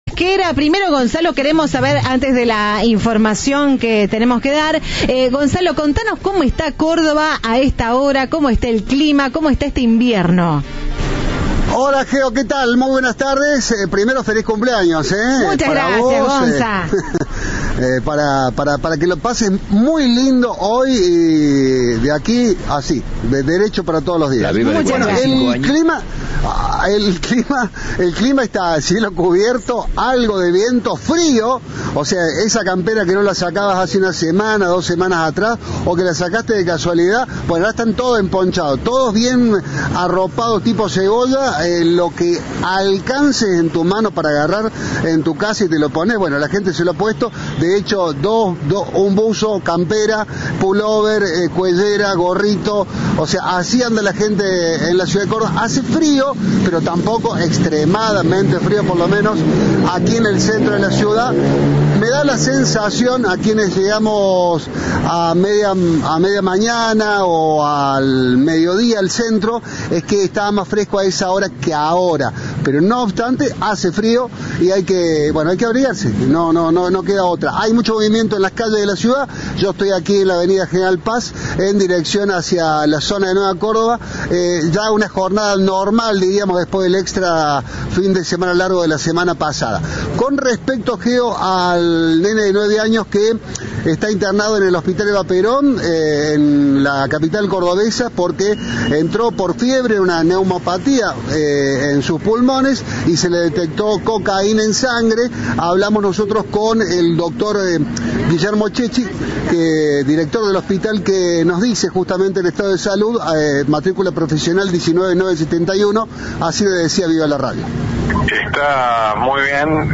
El ministro de Salud de la provincia, Ricardo Pieckenstainer, en diálogo con Cadena 3, expresó su preocupación.